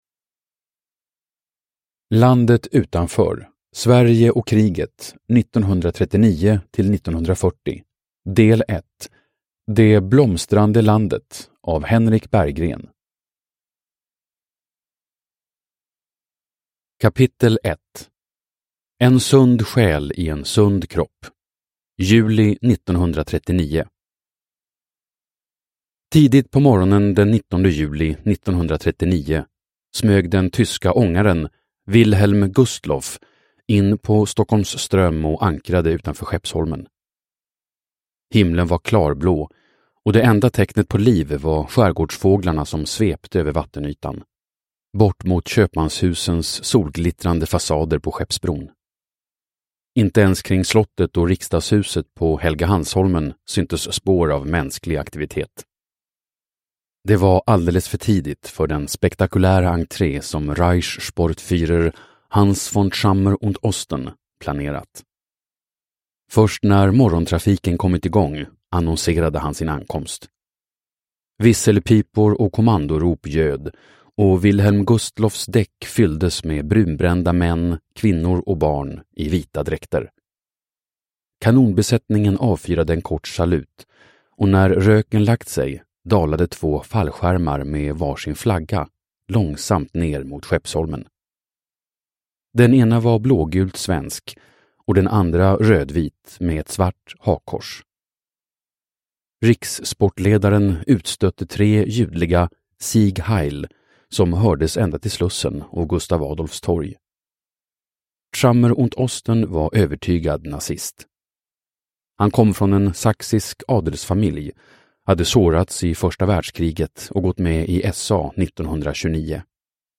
Landet utanför : Sverige och kriget 1939-1940. Del 1:1, Det blomstrande landet – Ljudbok – Laddas ner